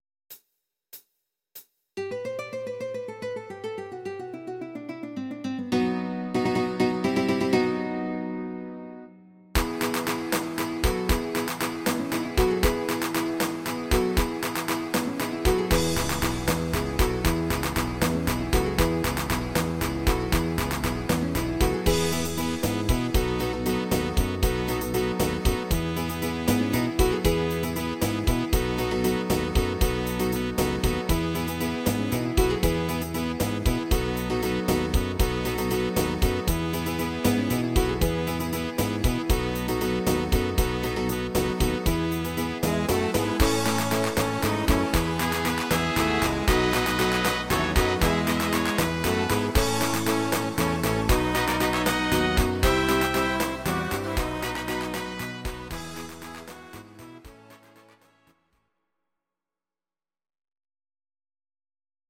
Audio Recordings based on Midi-files
German, Medleys